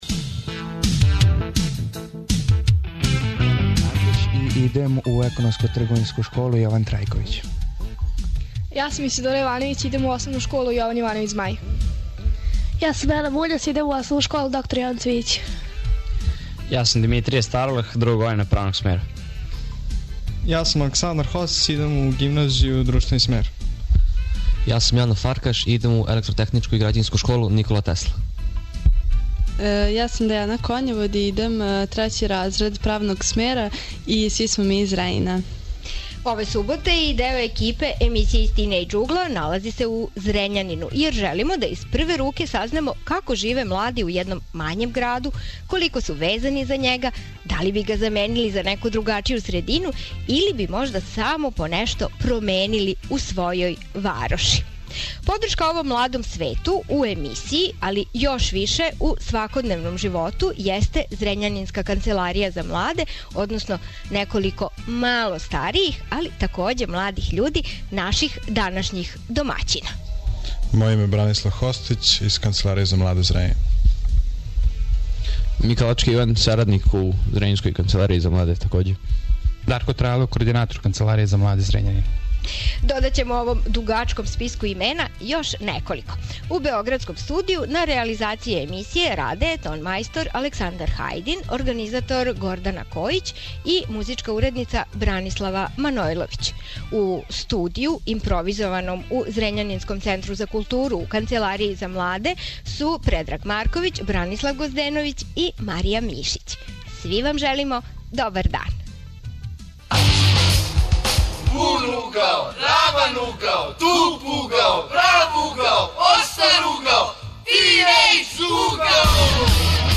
Емисија се реализује из Канцеларије за младе.